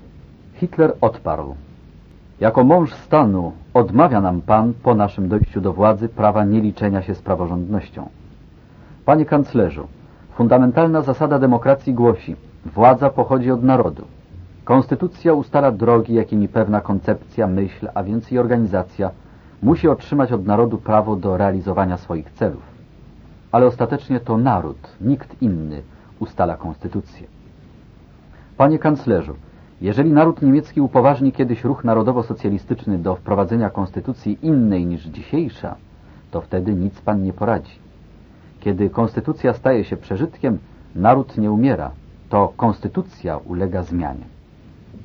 Co o tym wszystkim sądził Hitler? (fragment dyskusji z kanclerzem Papenem bądź Schleicherem, gdy próbowano „cywilizować” szefa NSDAP).